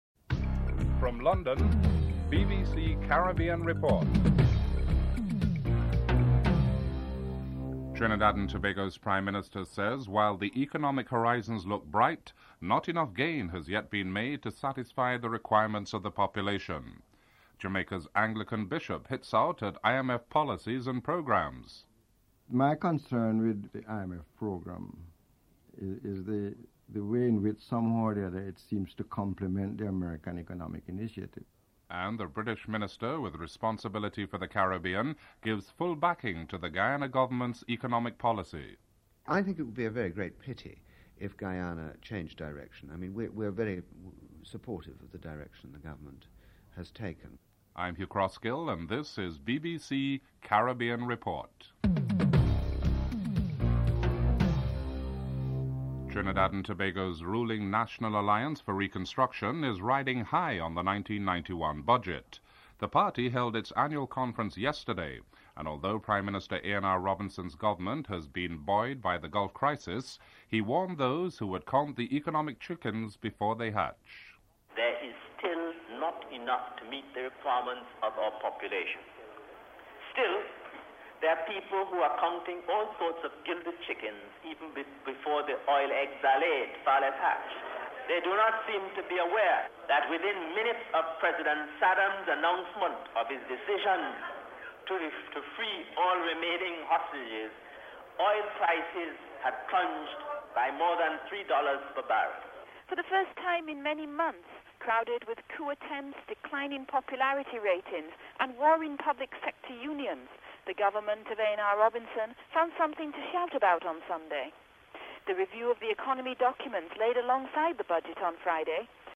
The British Broadcasting Corporation
1. Headlines (00:00-00:55)